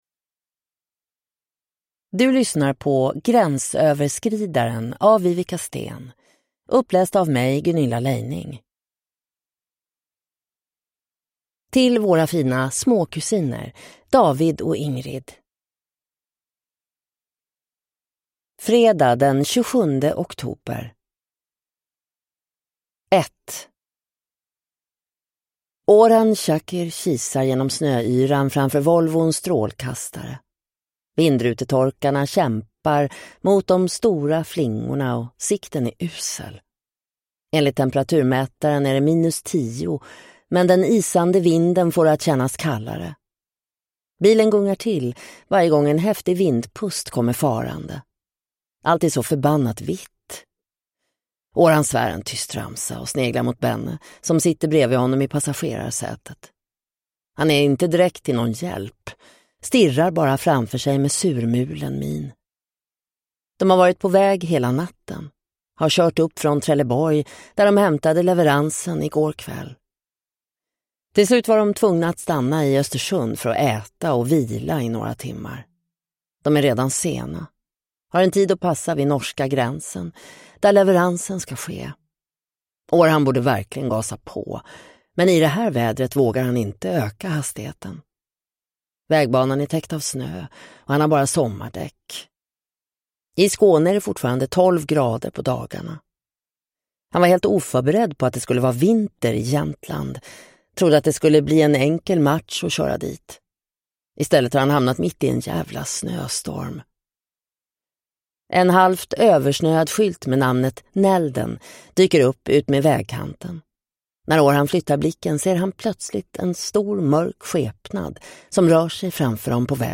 Gränsöverskridaren (ljudbok) av Viveca Sten